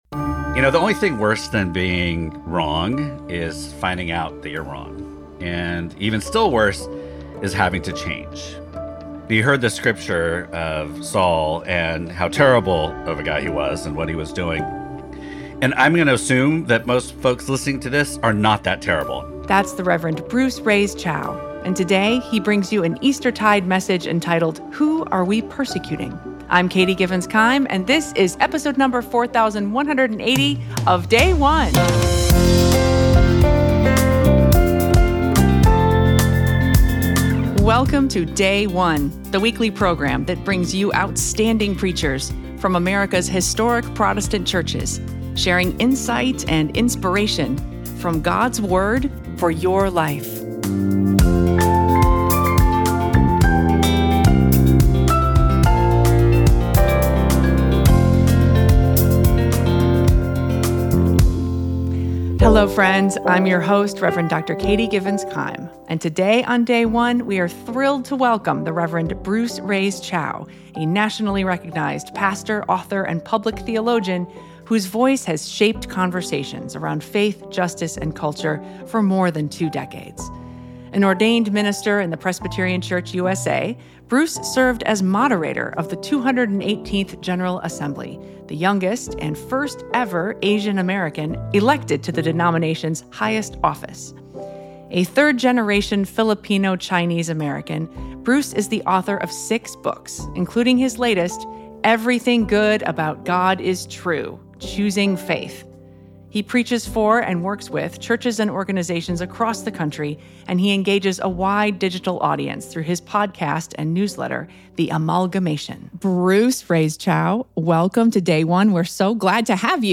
3rd Sunday of Easter - Year C Acts 9:1-20